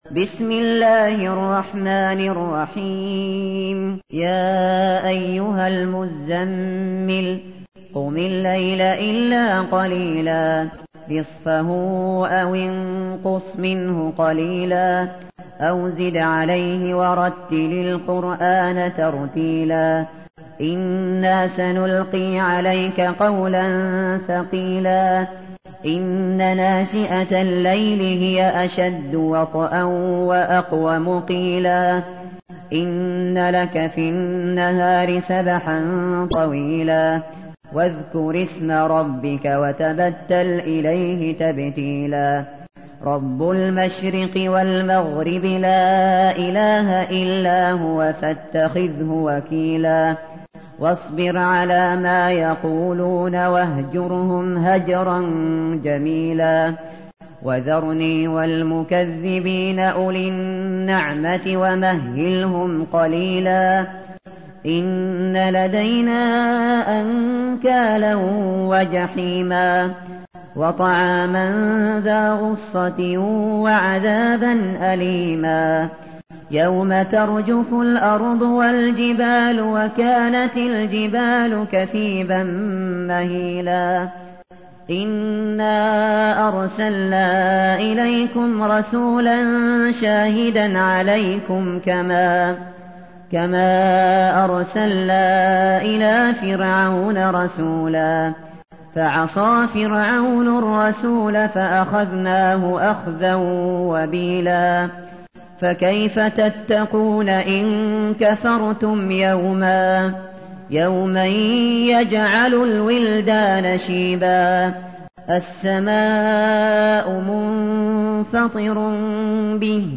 تحميل : 73. سورة المزمل / القارئ الشاطري / القرآن الكريم / موقع يا حسين